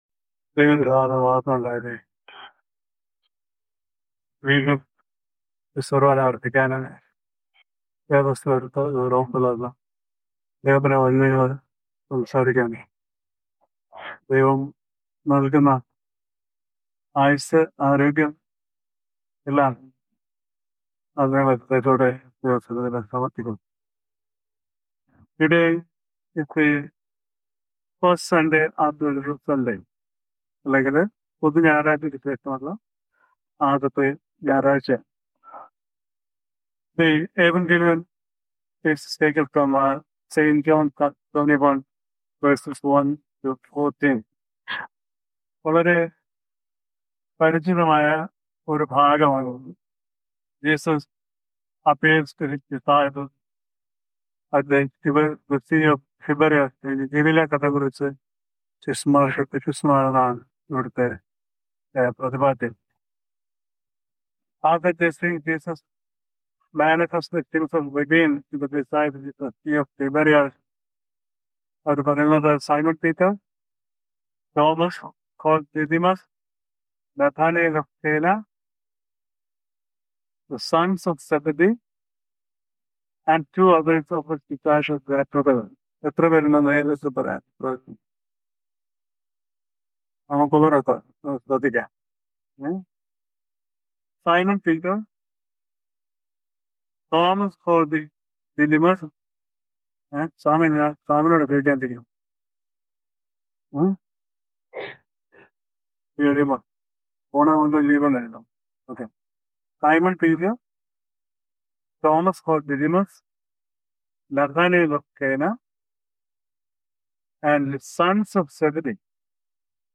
Sermon – April 19-2026 – St. John 21: 1-14